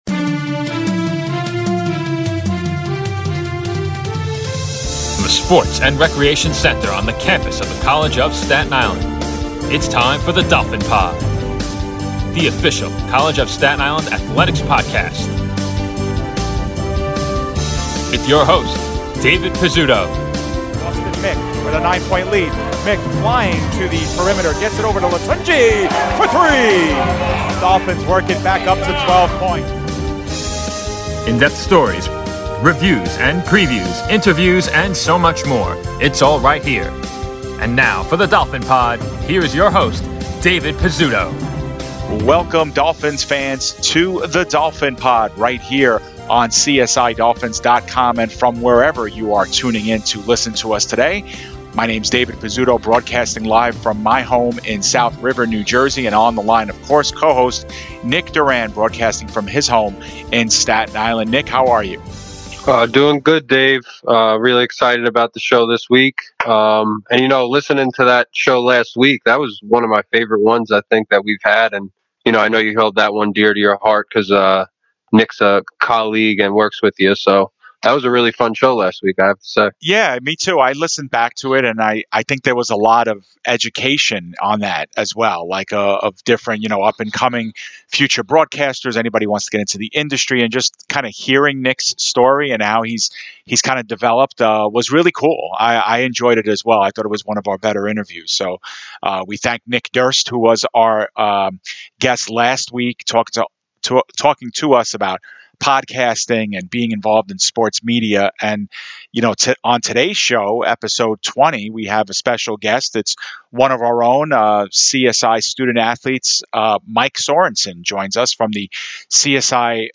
That and more on a great interview on this week's episode.